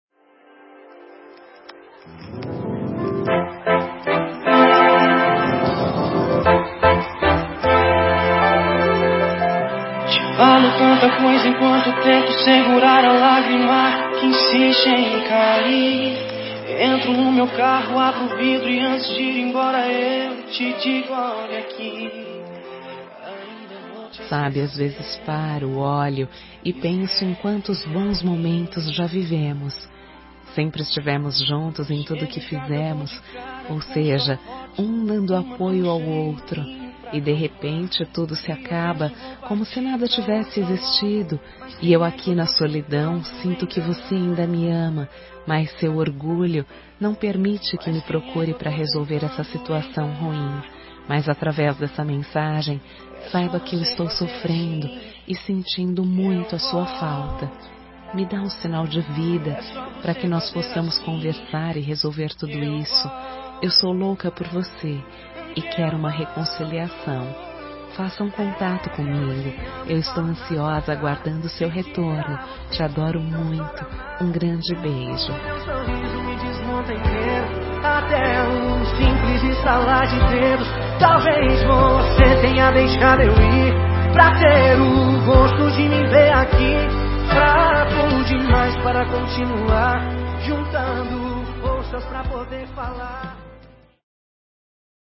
Telemensagem de Reconciliação – Voz Feminina – Cód: 09804